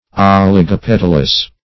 Search Result for " oligopetalous" : The Collaborative International Dictionary of English v.0.48: Oligopetalous \Ol`i*go*pet"al*ous\, a. [Oligo- + petal.]